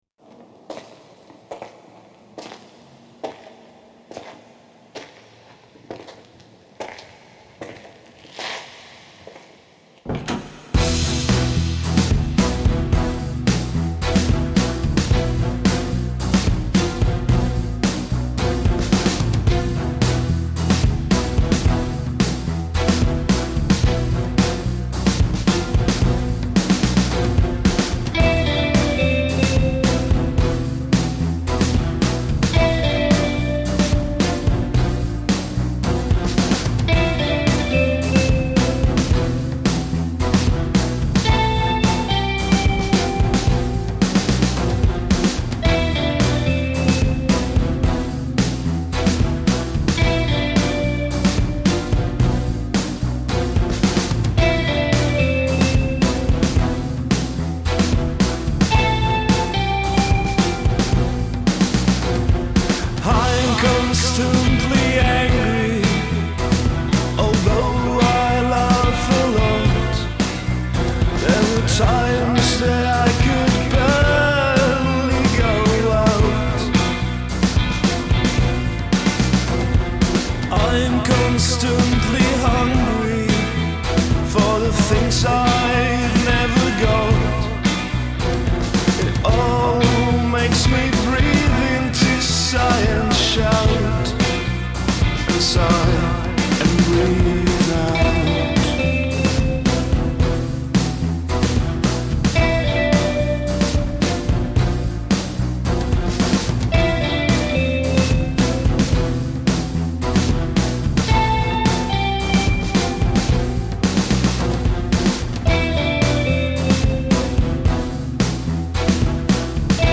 Žánr: Indie/Alternativa
Nahráno leden až květen 2016, Praha & Beroun